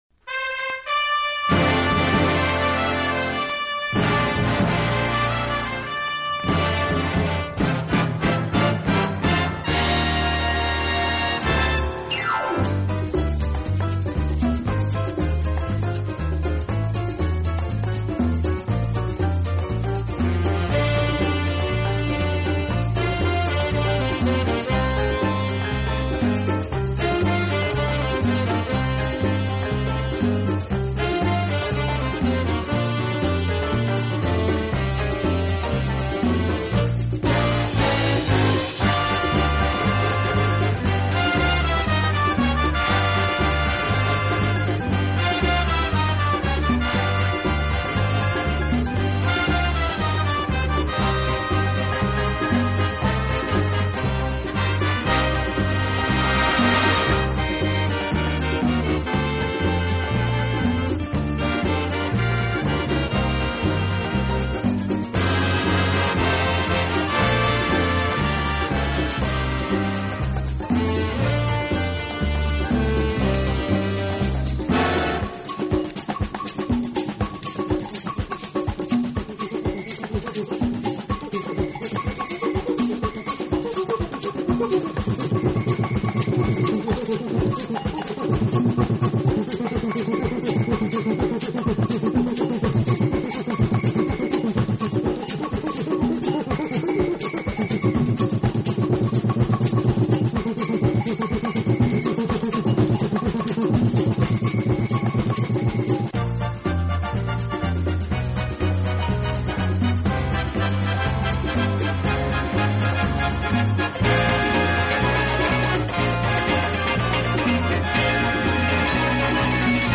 Genre:Jazz, Latin
Style:Easy Listening